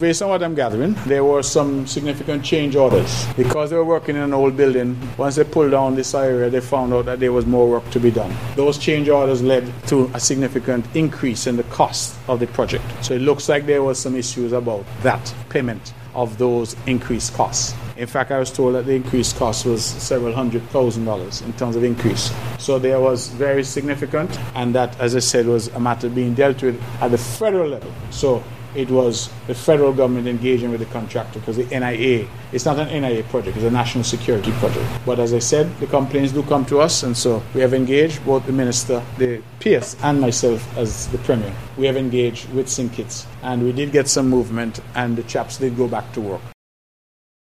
Premier, the Hon. Mark Brantley.